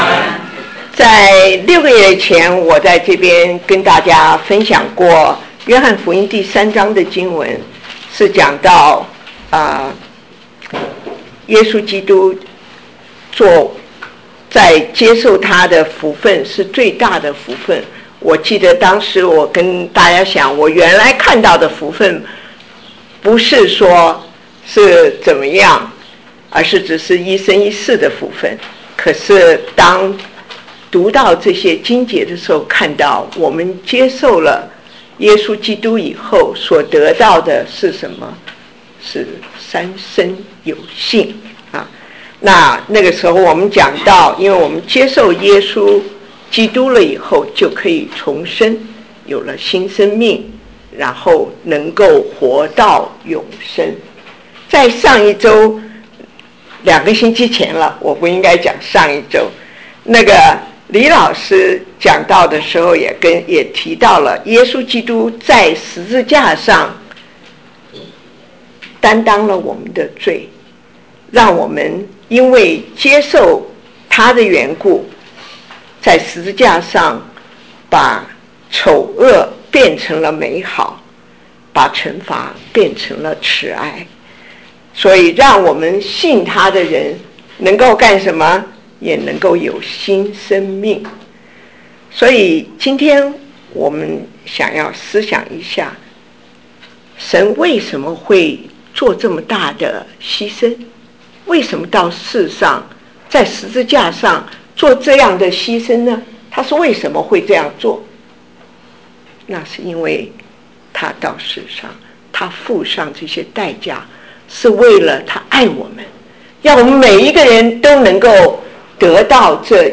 如何播放布道录音